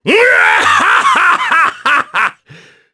Ricardo-Vox_Happy3_jp.wav